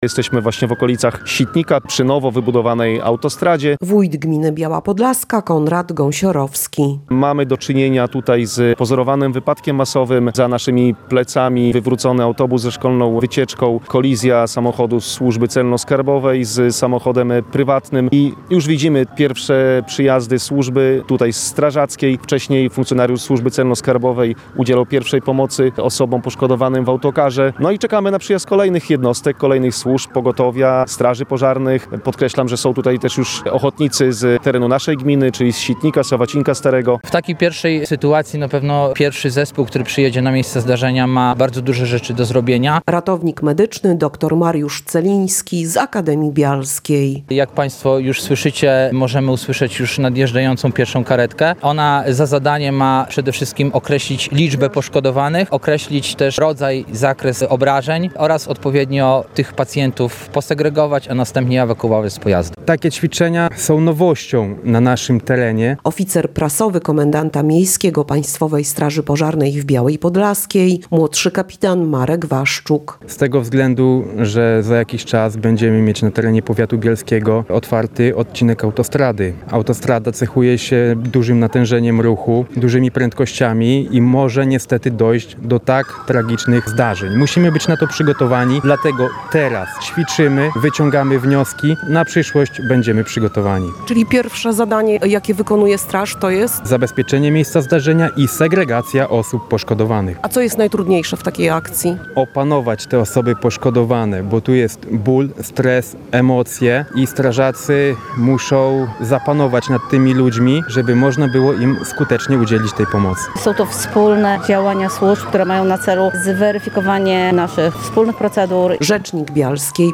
Jak przebiegała akcja, sprawdzała nasza reporterka.